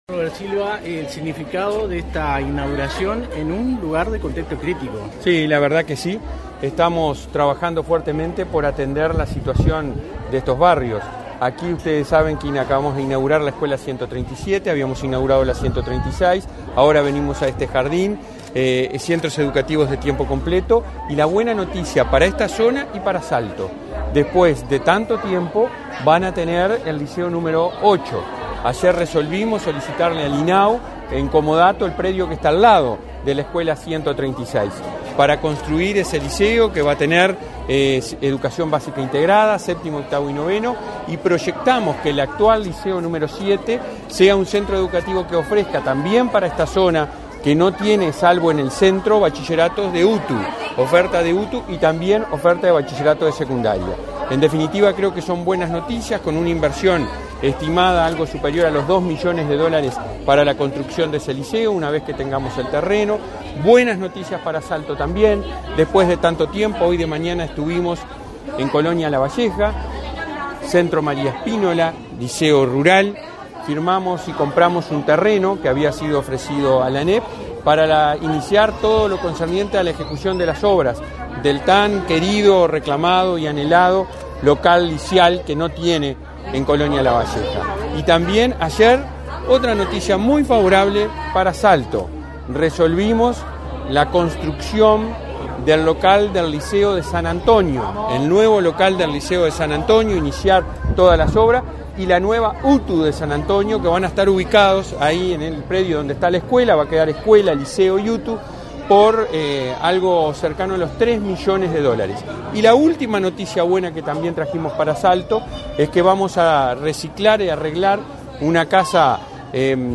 Declaraciones de prensa del presidente de la ANEP, Robert Silva
En la oportunidad, realizó declaraciones a la prensa.